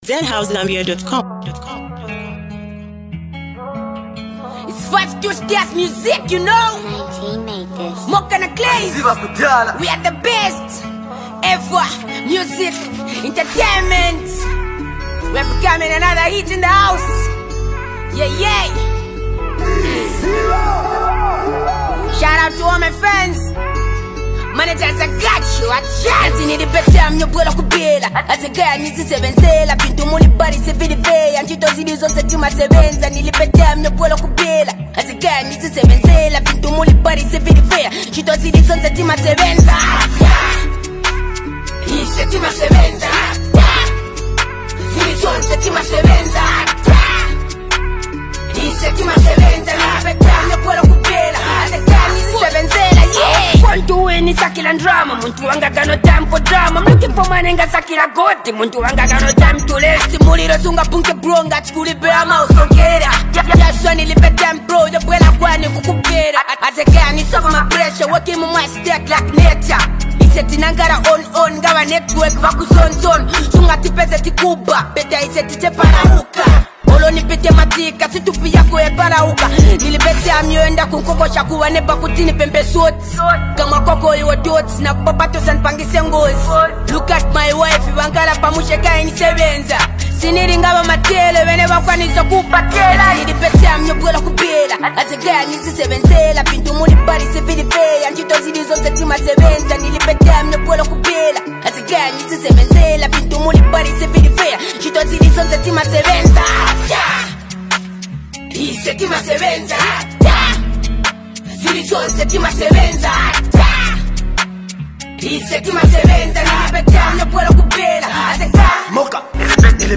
on a hard-hitting beat